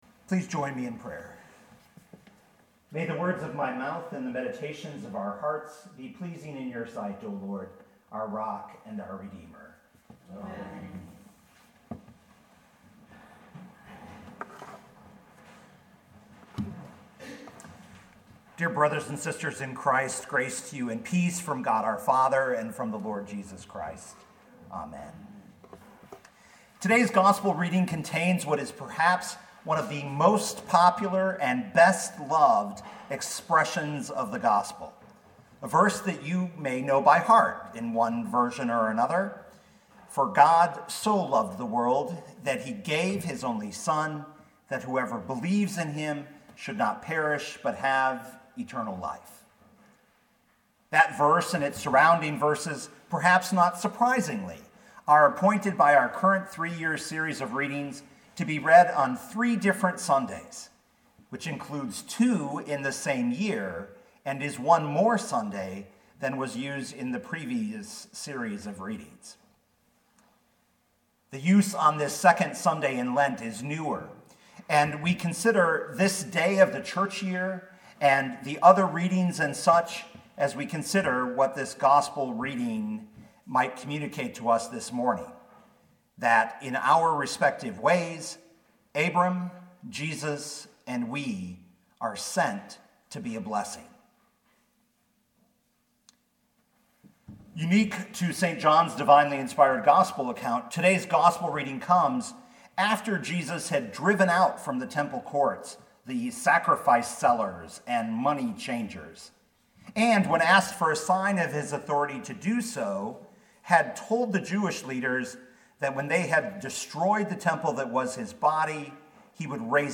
2020 John 3:1-17 Listen to the sermon with the player below, or, download the audio.